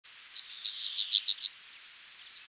bird3.mp3